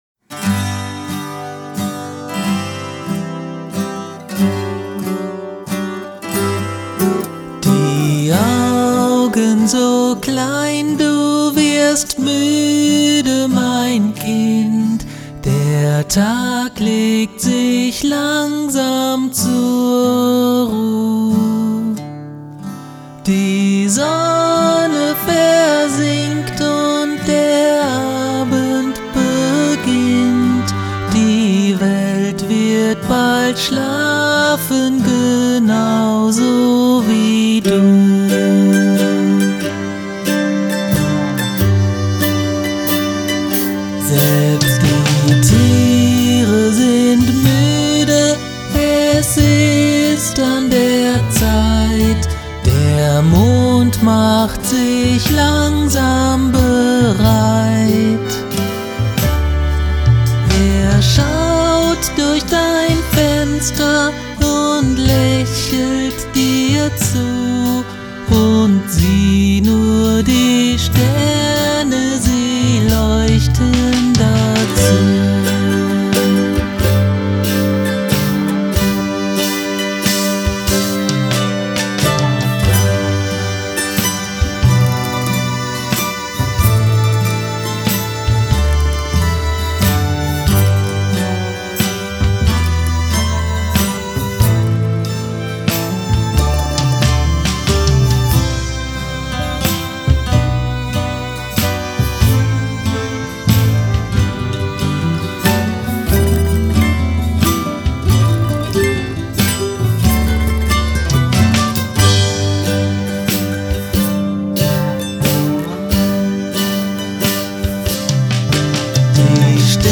Schlaflieder